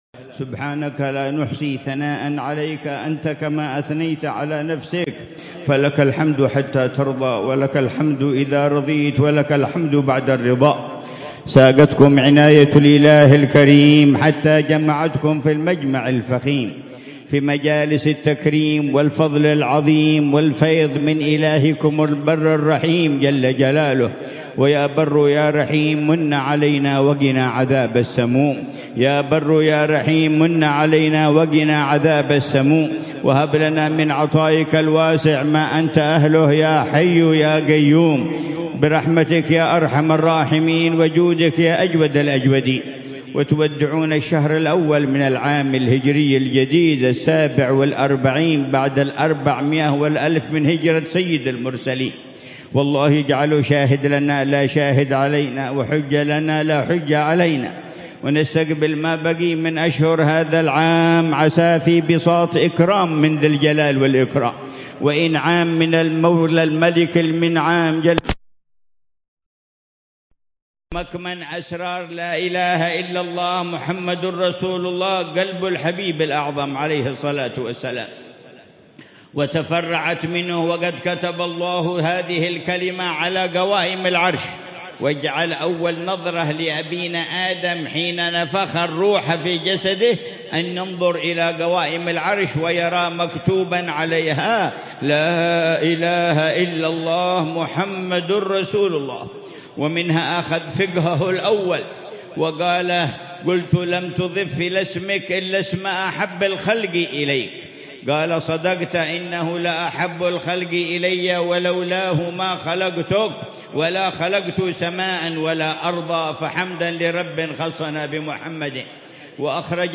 مذاكرة العلامة الحبيب عمر بن حفيظ في مجلس الذكر والتذكير في حضرة الإمام أحمد بن زين الحبشي بالحوطة، وادي حضرموت يوم الأحد 25 محرم 1447هـ